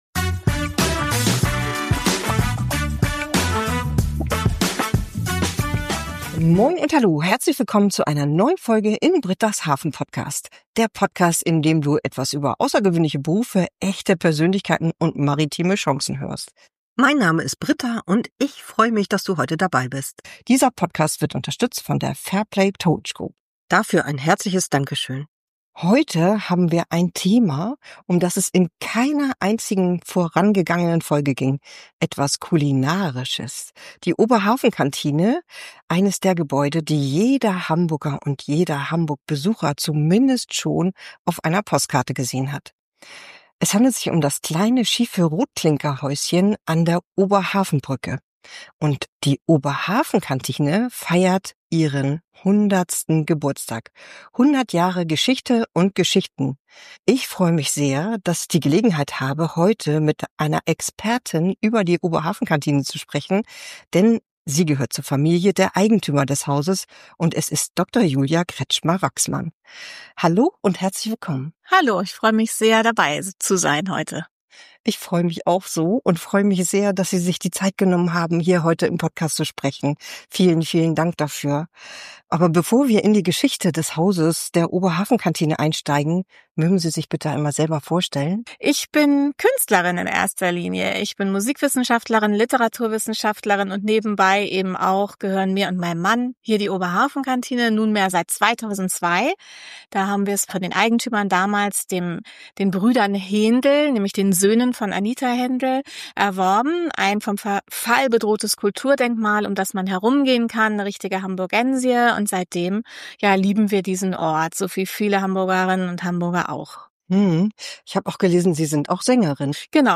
Ein Gespräch bei dem es auch um die Seele eines ganz besonderen Ortes geht.